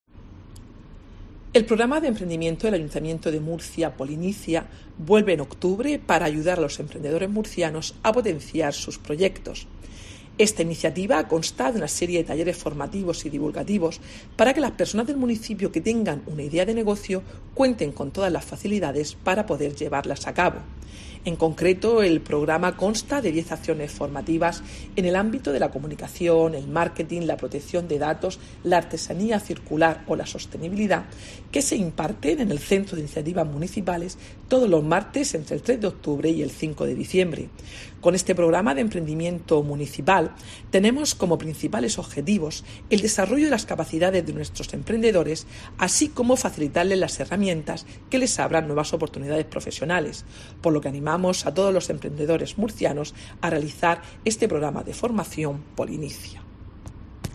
Mercedes Bernabé, concejal de Gobierno Abierto, Promoción Económica y Empleo